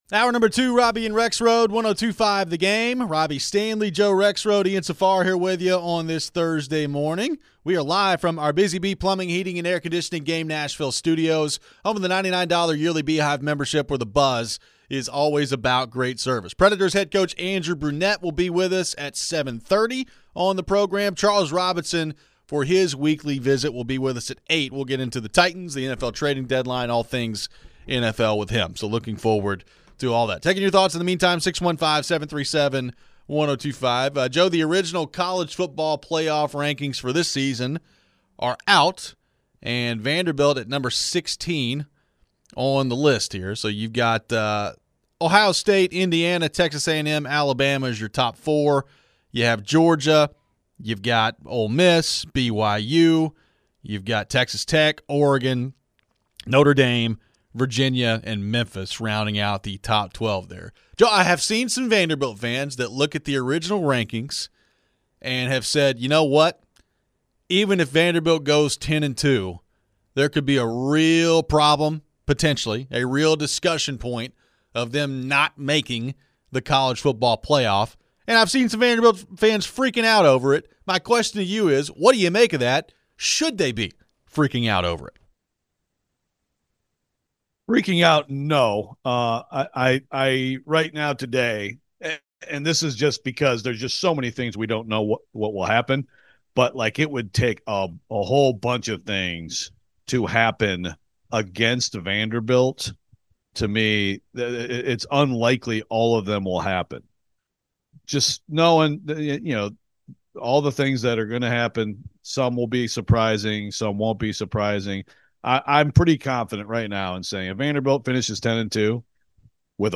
What are the outlooks for some of the other SEC teams? We transition to some hockey talk as Preds HC Andrew Brunette calls into the show.